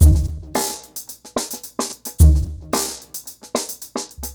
RemixedDrums_110BPM_11.wav